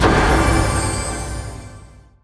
levelup1_1.wav